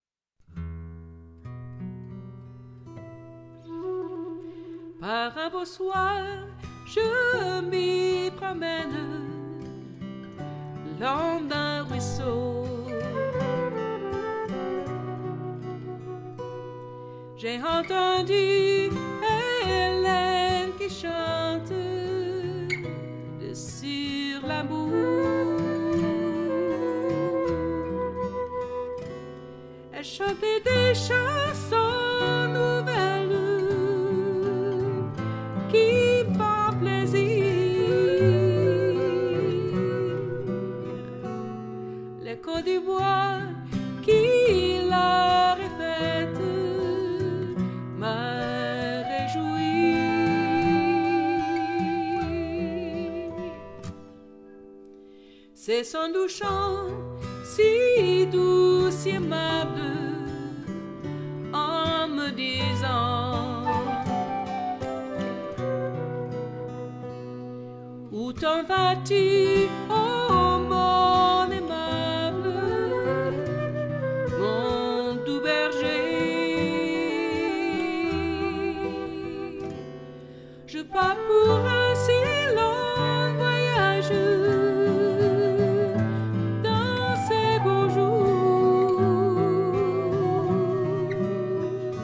guitare
flûte
brosses